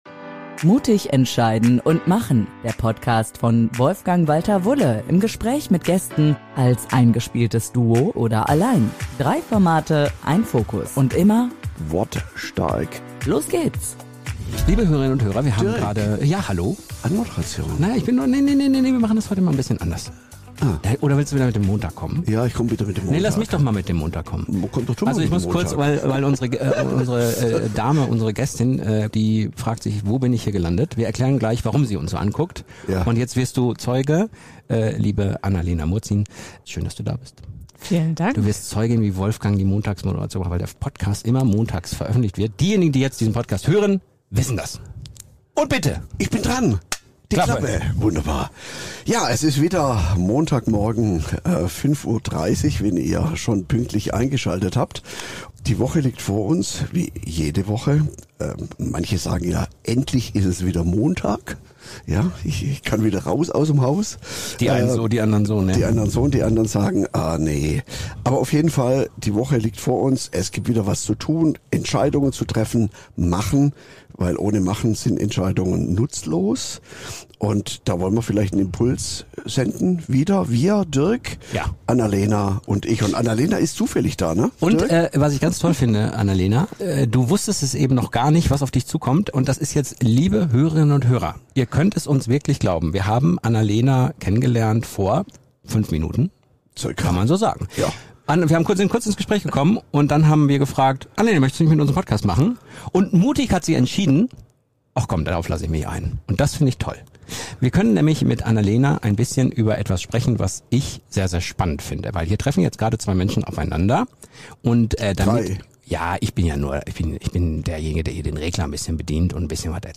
Was folgt, ist ein lebendiges, humorvolles und zugleich tiefes Gespräch über Persönlichkeit, Muster und echte Veränderung: Warum unser Gehirn auf Automatismen setzt, weshalb Perfektion und Kontrolle oft nur Sicherheit simulieren – und wieso Entwicklung erst möglich wird, wenn man erkennt, was man bisher nicht gesehen hat. In dieser Folge geht es unter anderem um Persönlichkeitstypen, Veränderung in Teams und Einzelpersonen, intrinsische Motivation, Offenheit als Kompetenz – und der Praxis-Test mit der Zahnbürste (rechte vs. linke Hand) Eine Folge, die zeigt: Spontane Entscheidungen können erstaunlich gute Ergebnisse bringen – wenn Vertrauen, Klarheit und ein bisschen Mut mit am Tisch sitzen.